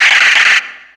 Cri de Branette dans Pokémon X et Y.